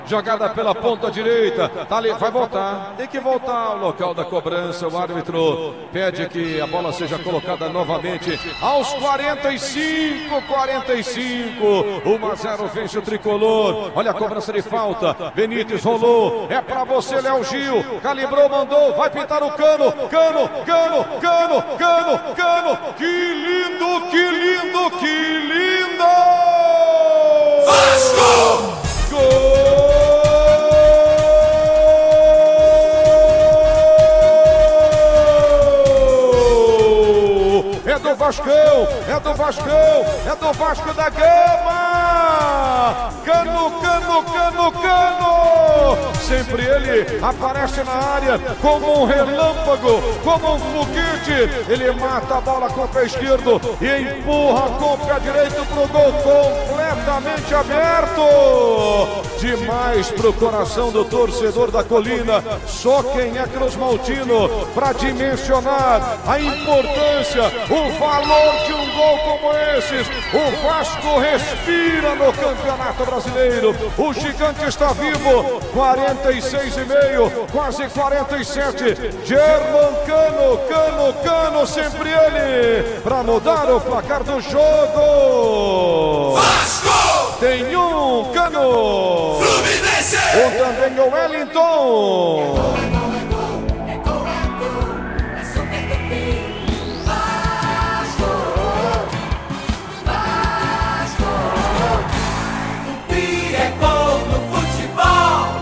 GOL-VASCO.mp3